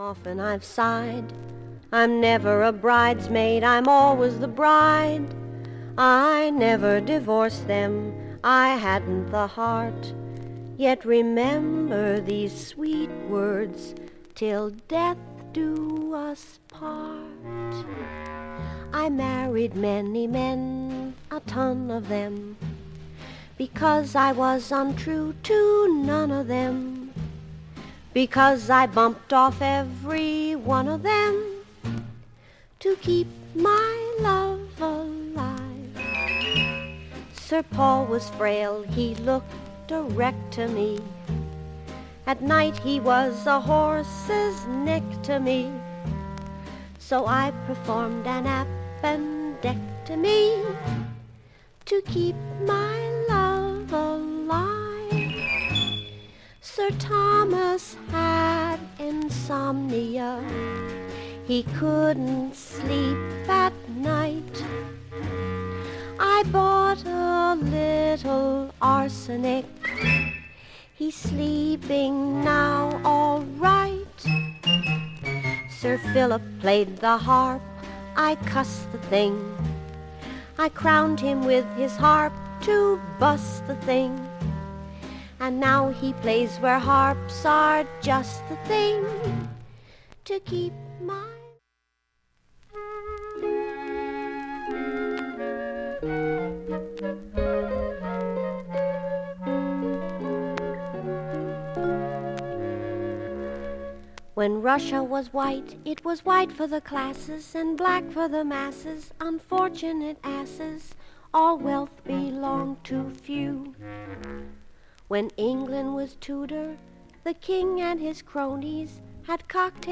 無音部などのチリ音もありません。
クリアな音質良好全曲試聴済み。
(1m31s〜)A-5始めかすかなプツが８回出ます。
ほか３回までのかすかなプツが２箇所
単発のかすかなプツが７箇所
◆ＵＳＡ盤オリジナルMono